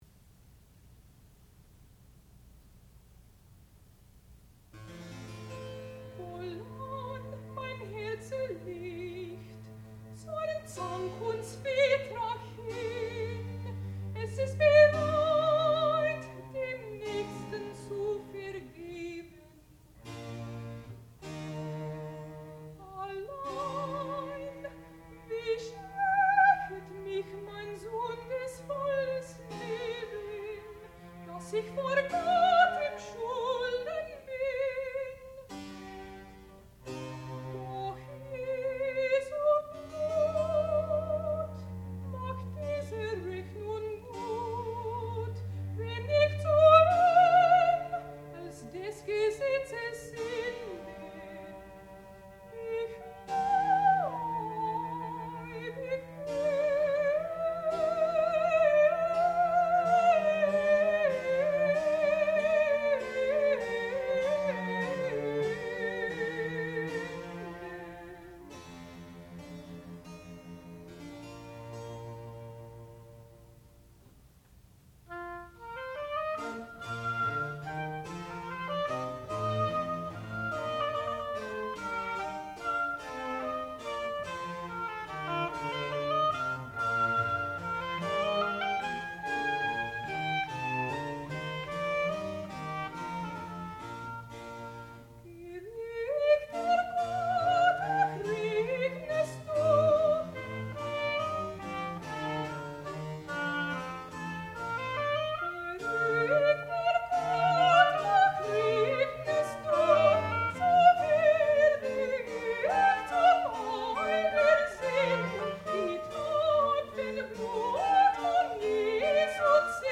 sound recording-musical
classical music
violoncello
harpsichord
oboe